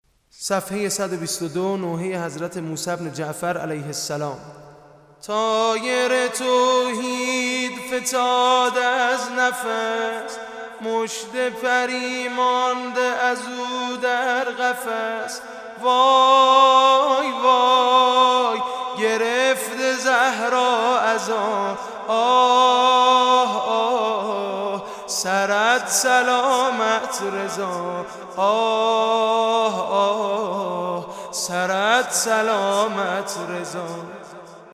نوحه شهادت حضرت موسي ابن جعفر (ع) ( طایــر توحیــد فتــاد از نفس)